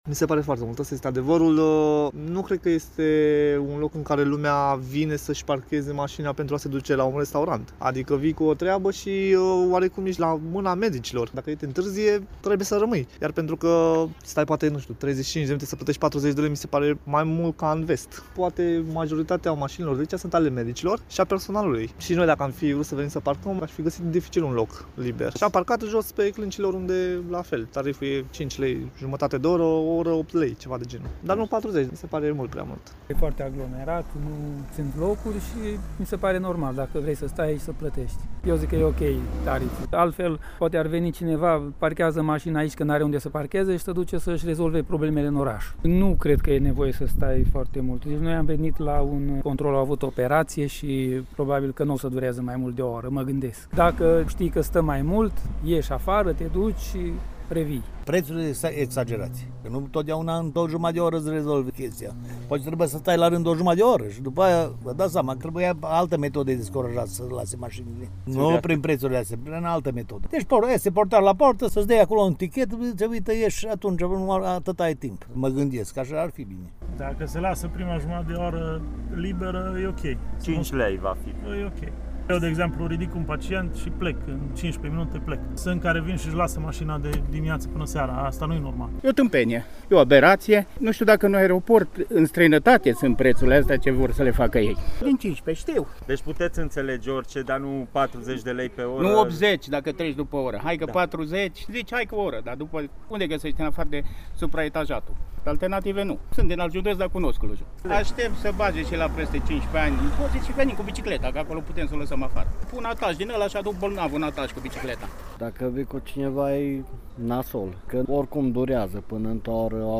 Voxuri-parcare-spital.mp3